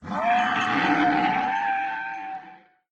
mob / horse / zombie / death.ogg
death.ogg